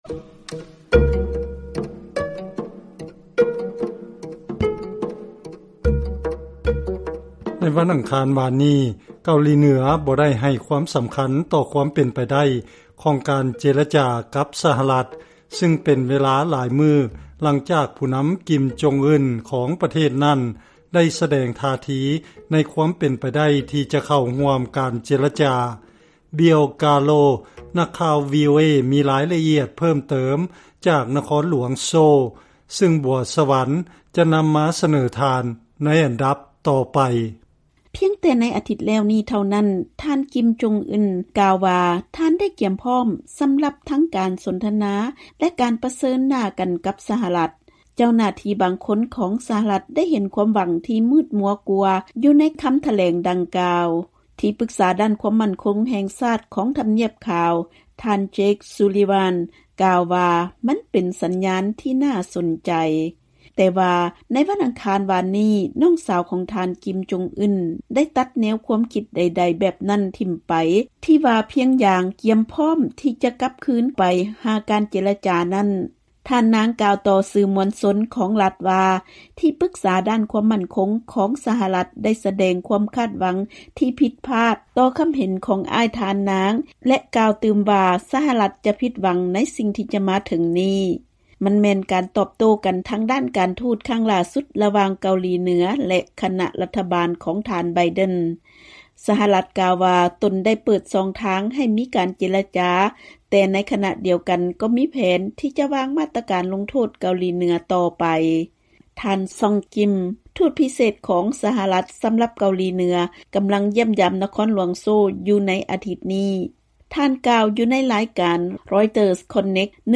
ເຊີນຟັງລາຍງານກ່ຽວກັບສາຍພົວພັນລະຫວ່າງສະຫະລັດ ກັບເກົາຫລີເໜືອ